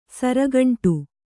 ♪ sara gaṇṭu